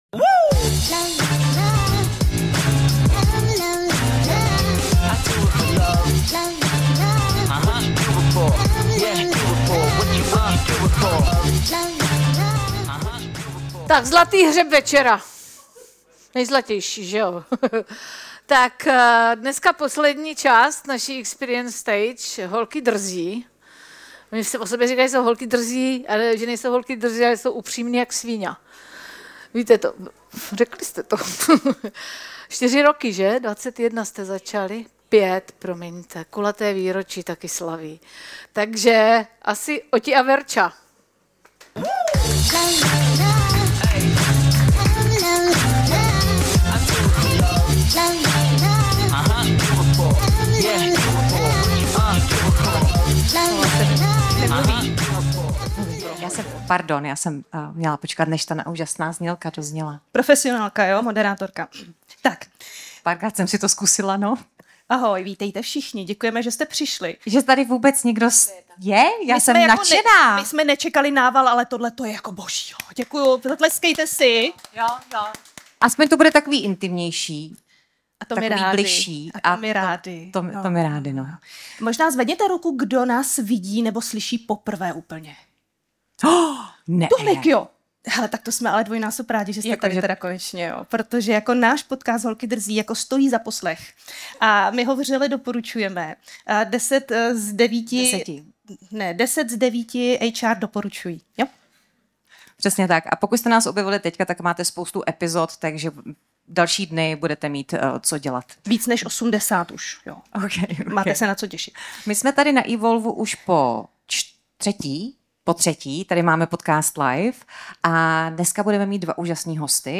Záznam live podcastu z Evolve! Summit 2025